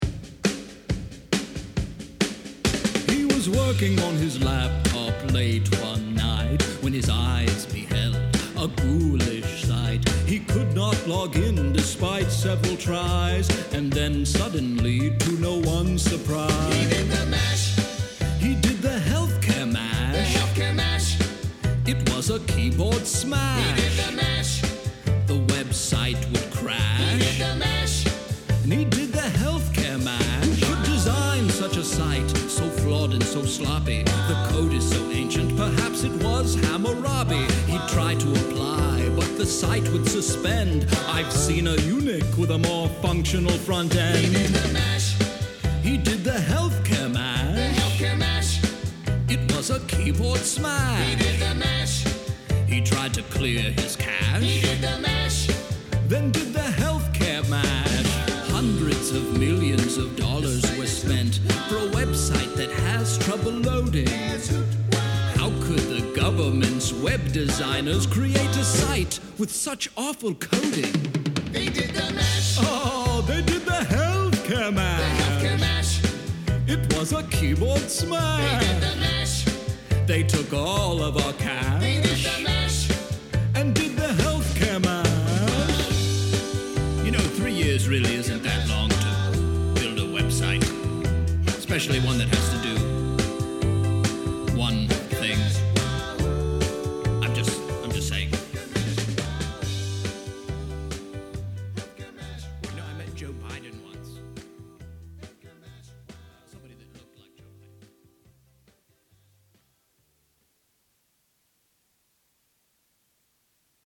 mash-up